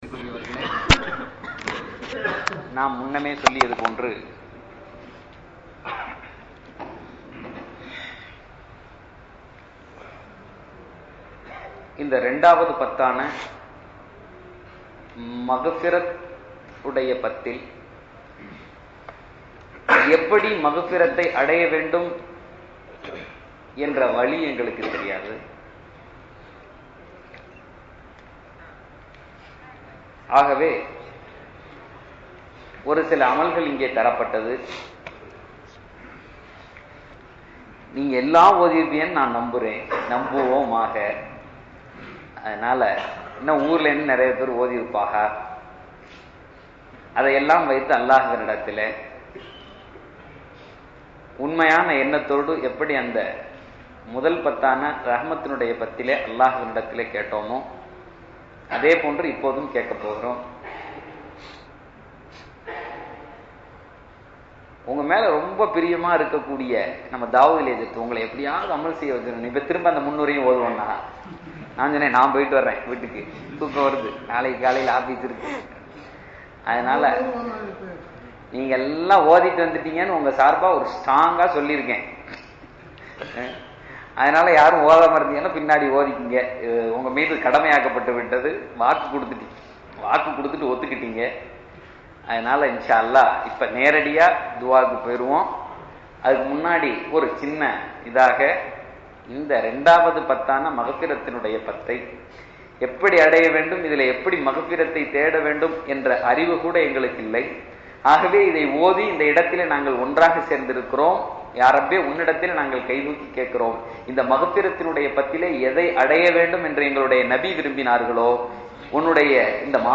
ரமளான் இரண்டாவது பத்தில் கேட்கப்பட்ட துஆ ரமளானின் இரண்டாவது பத்து பாவ மன்னிப்பிற்க்காக உள்ளது. இந்த அற்புத துஆ துபை கோட்டைப் பள்ளியில் வைத்துக் கேட்கப்பட்டது.